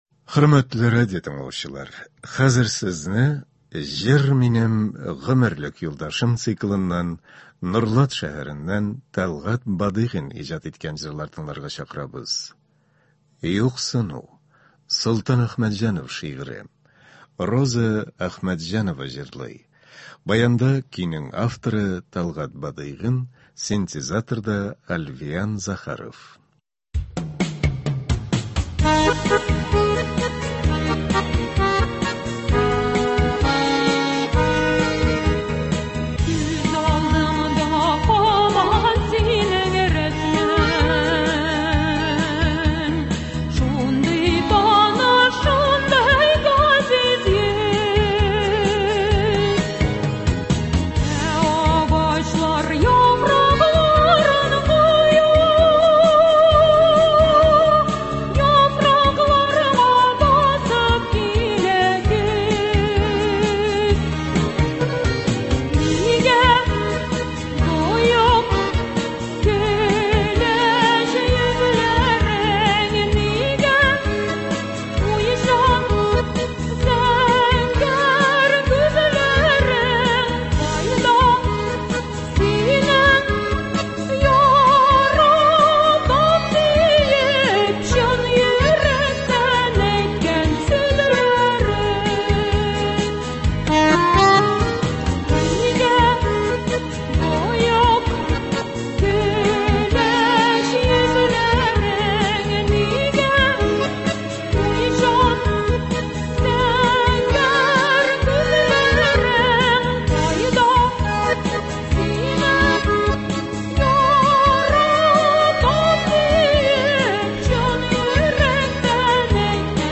Концерт (24.04.23)